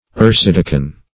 Meaning of ercedeken. ercedeken synonyms, pronunciation, spelling and more from Free Dictionary.
Search Result for " ercedeken" : The Collaborative International Dictionary of English v.0.48: Ercedeken \Er`ce*de"ken\, n. [OE., fr. pref. erce- = archi- + deken a deacon.]